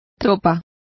Complete with pronunciation of the translation of troops.